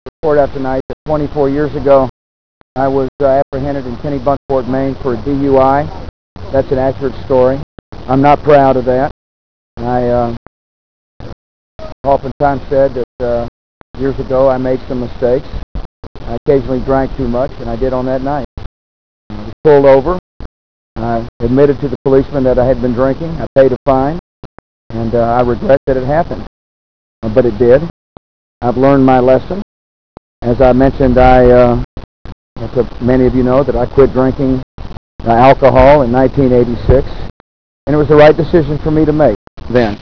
–Ross Perot, founder of the Reform Party, endorsing Republican George Bush Jr's DUI arrest confession on CNN's Larry King Live, November 2, 2000
Bush Lite confession (wav)